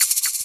Perc (9).wav